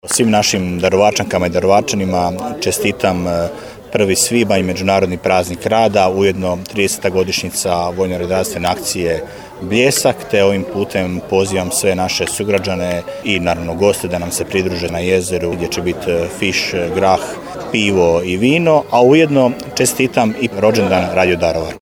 Pučka proslava na Športskom ribnjaku Jezero
Objedinjenu čestitku za praznik Daruvarčankama i Daruvarčanima, radnicima, braniteljima, ljudima dobre volje i zaposlenicima i slušateljima Radio Daruvara  uputio je gradonačelnik Daruvara Damir Lneniček: